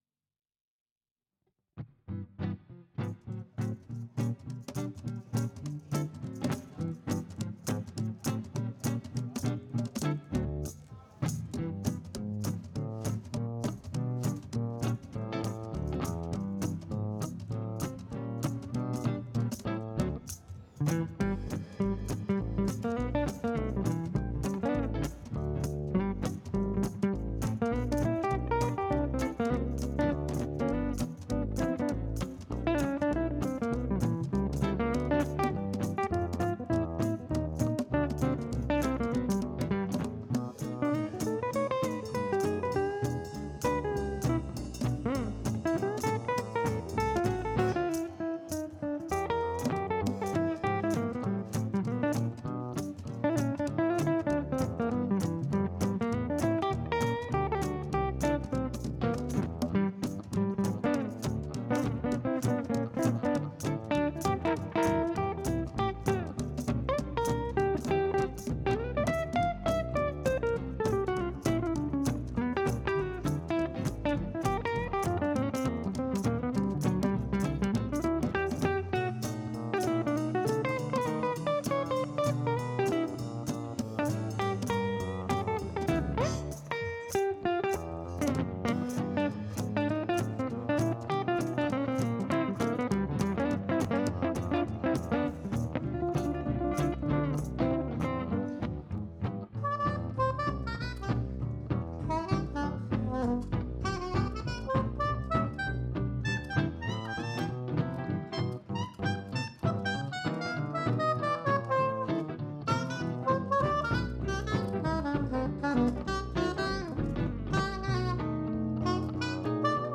I have some feed back for myself ; the percussion is panned to much to the right should be more center, oops .
The percussion player doubles on sax need to a better fad in the transition.
The percussion sounds too far left to me!
Is there more bottom to be found in that bass track?
It sounds suuuper quiet overall.
Percussion may still be a bit too far left !